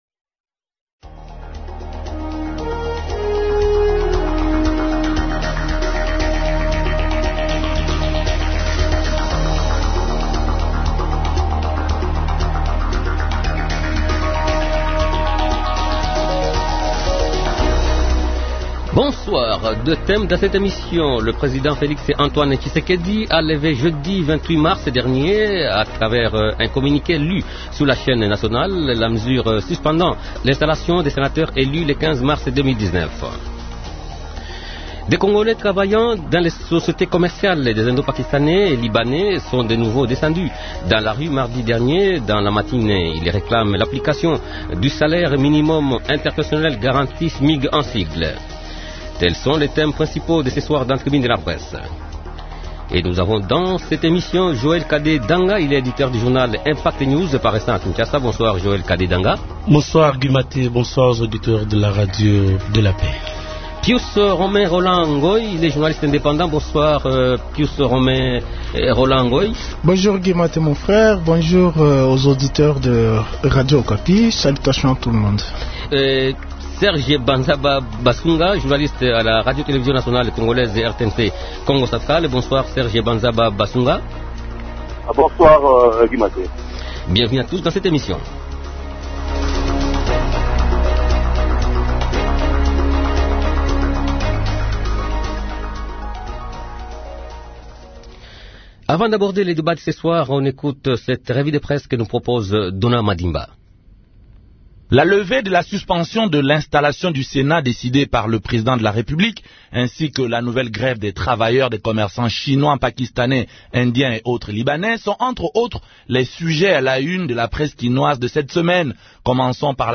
Deux thèmes dans cette émission :